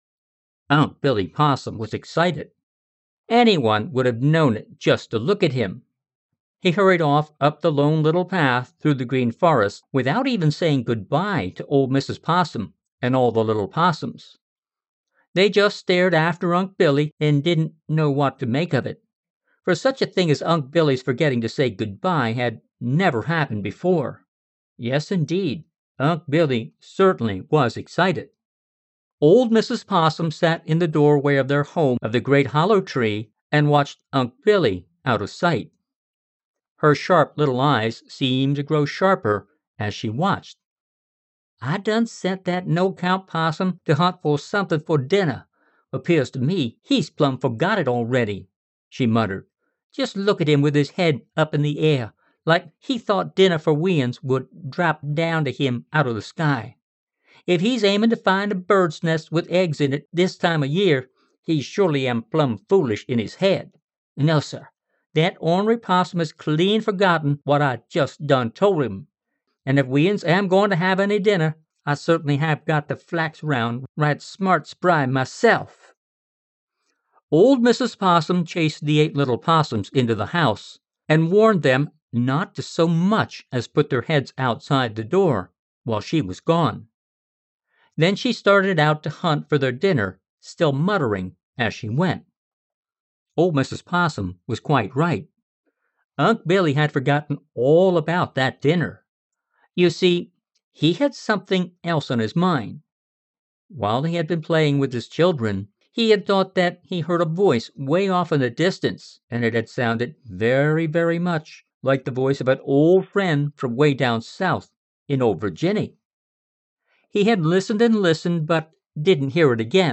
Click on the image to go to the Audible page for this audiobook.